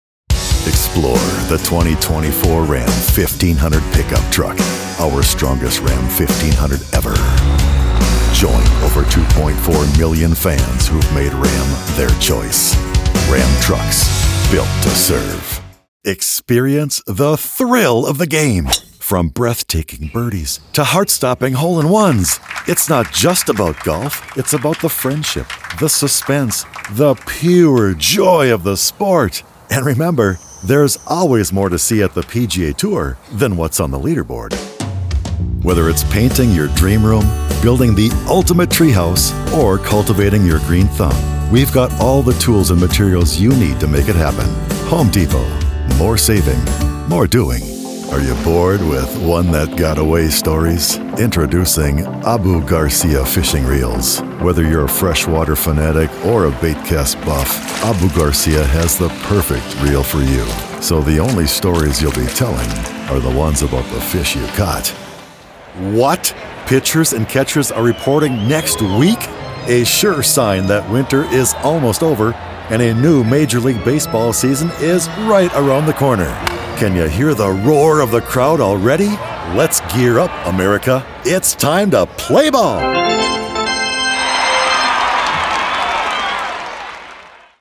Middle Aged
With his natural and rich bass, he effortlessly shifts from a conversational and engaging style to a powerful promo style.